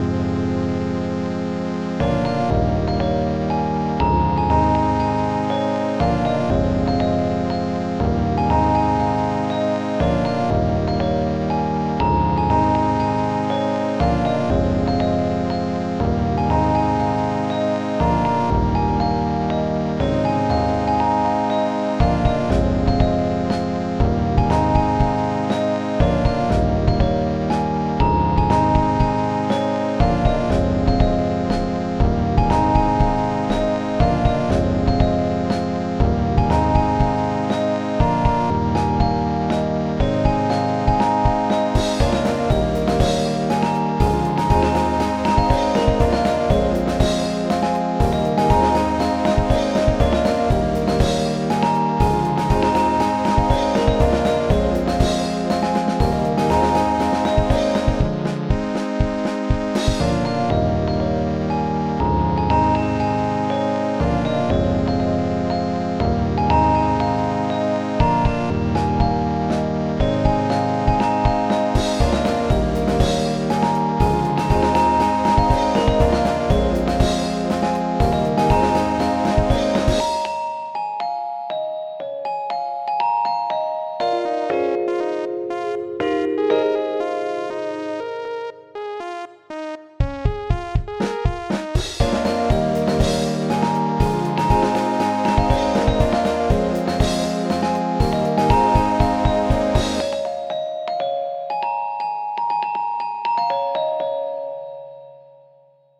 glockenspiel_symphony.mp3